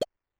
New grunk collection SFX
collect4.wav